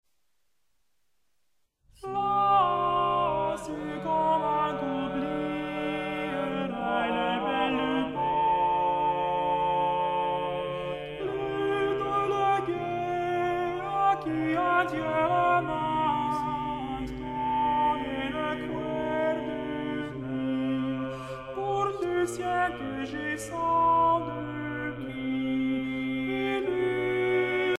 Musique médiévale
Pièce musicale éditée